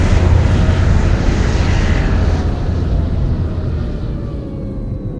landing.ogg